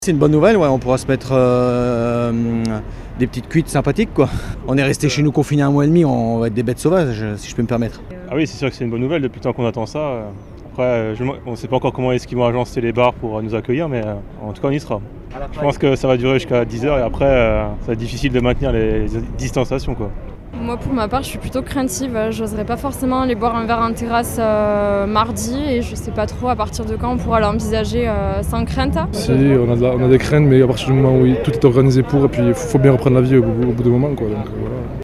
Reportage à Toulouse